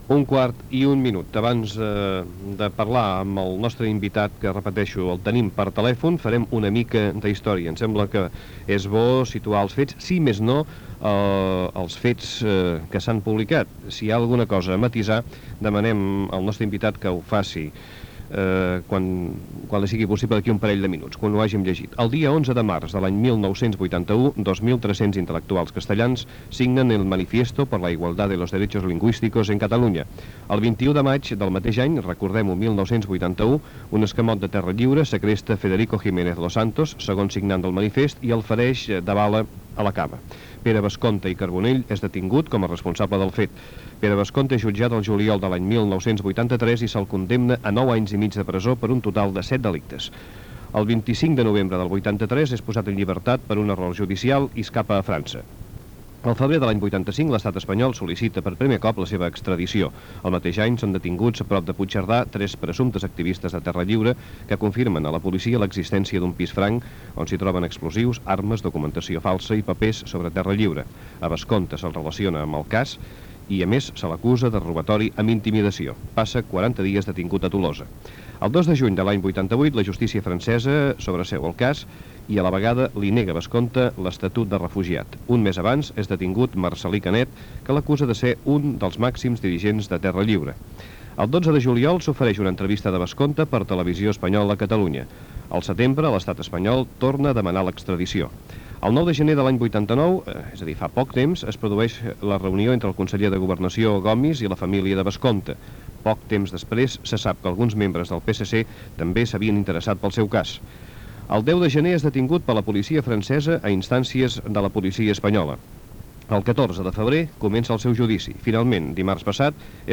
Presentació i entrevista telefònica
Info-entreteniment